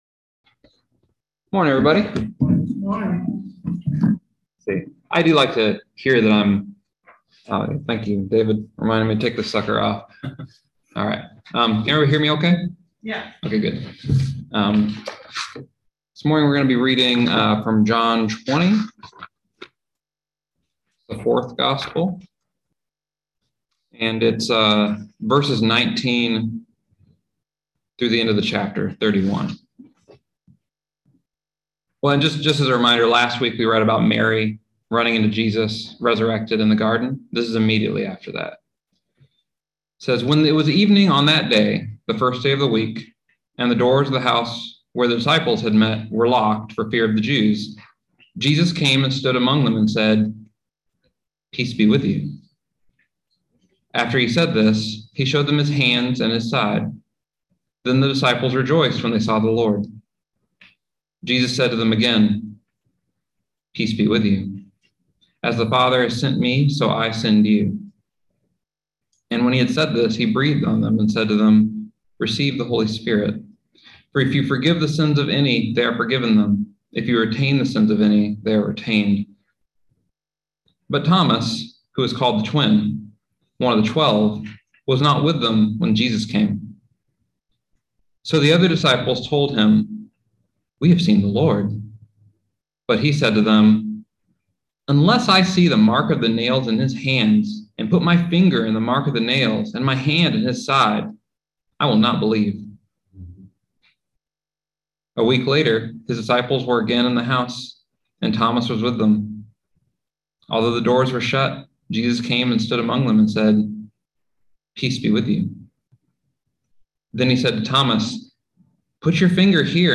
Listen to the most recent message from Sunday worship at Berkeley Friends Church, “Can You Believe it?”